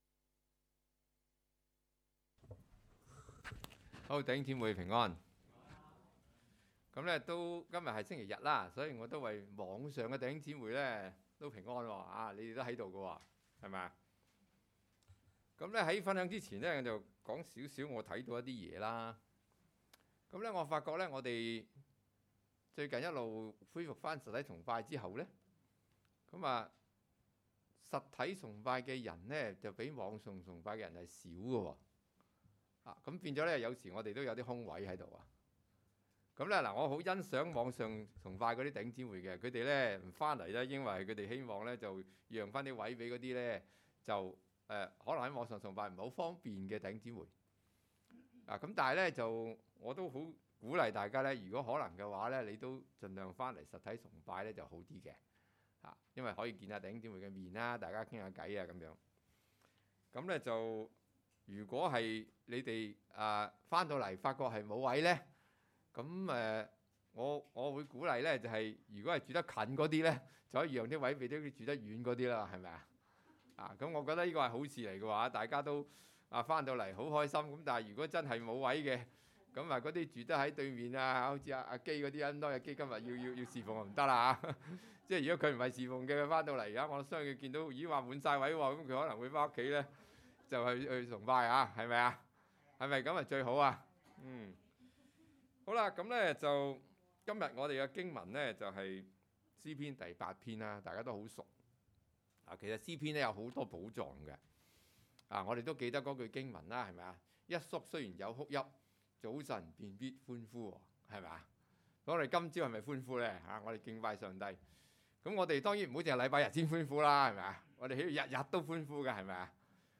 講道： 這是天父世界